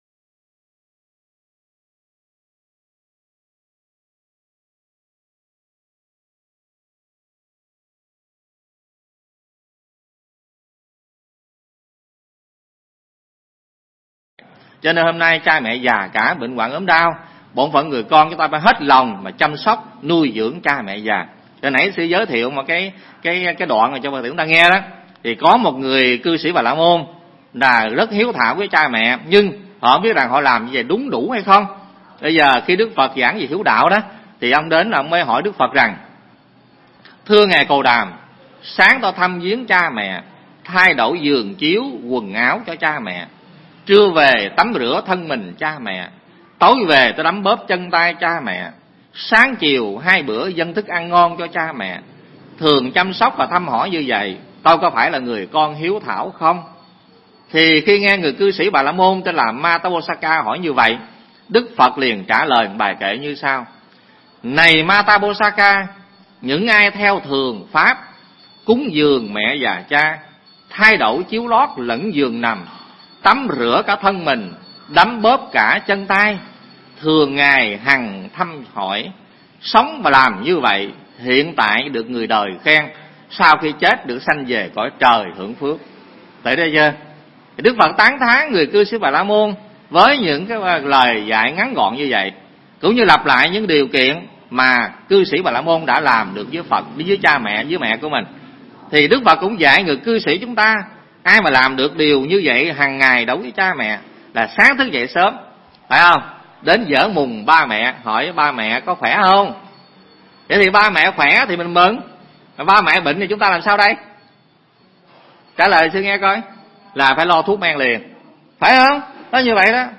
Tải mp3 Thuyết Pháp Hiếu Đạo Của Người Con Phật Phần 2